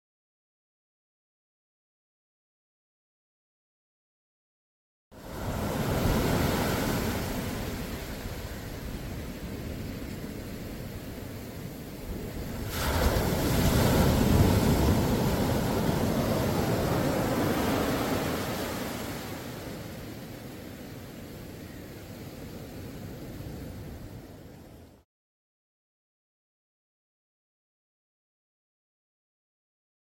This is a beautiful, relaxed sound effects free download
This is a beautiful, relaxed setting....gentle early morning waves.